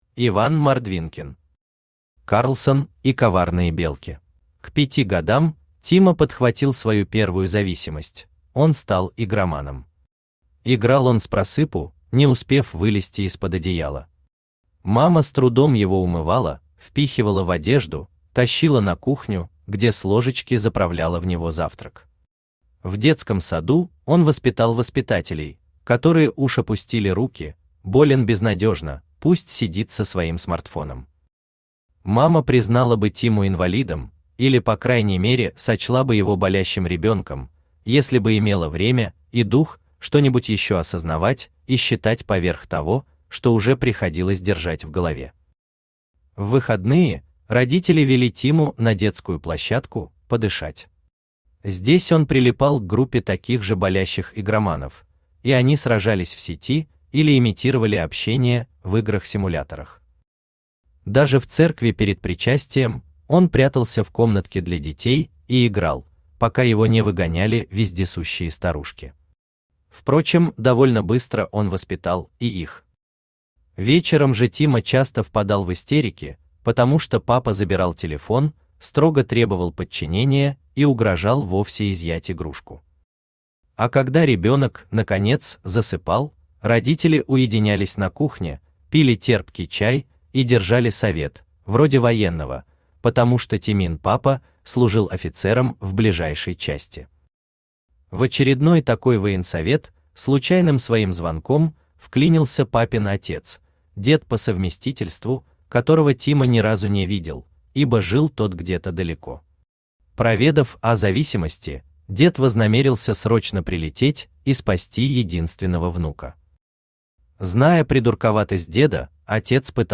Аудиокнига Карлсон и коварные белки | Библиотека аудиокниг
Aудиокнига Карлсон и коварные белки Автор Иван Александрович Мордвинкин Читает аудиокнигу Авточтец ЛитРес.